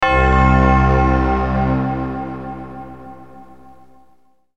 game_over1.wav